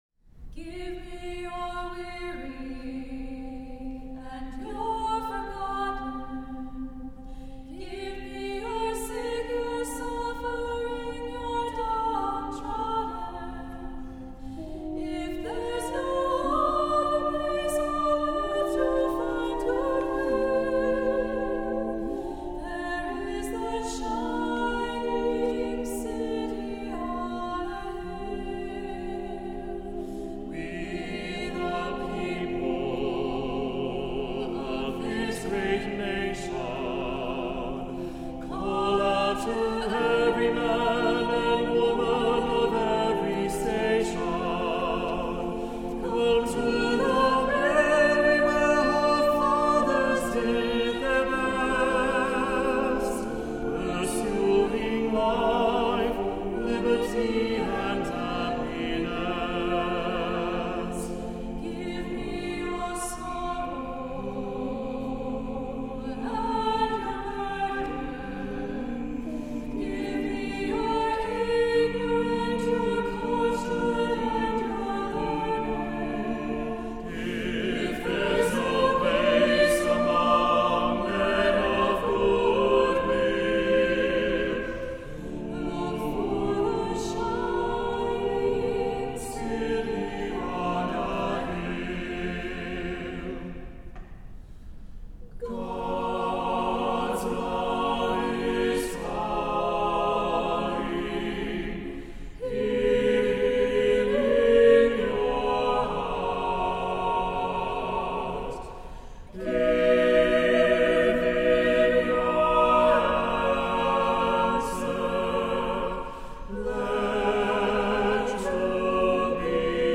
Voicing: SATTBB a cappella